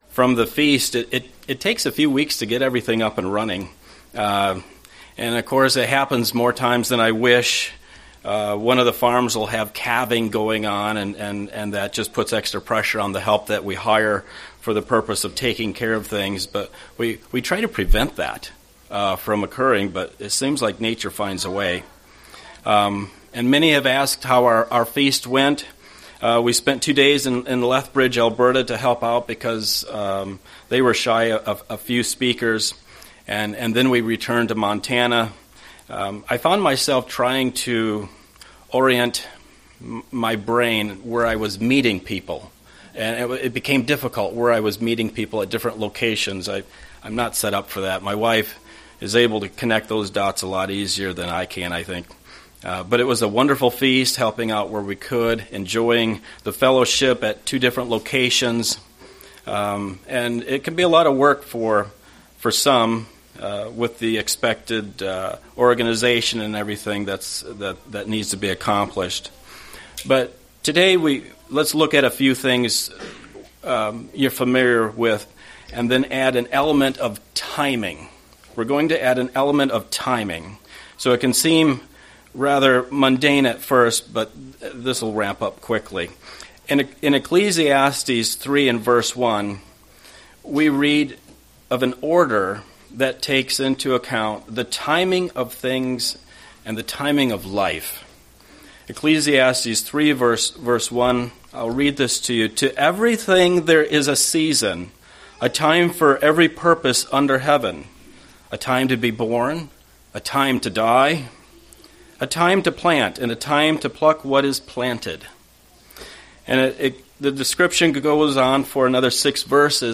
Given in Milwaukee, WI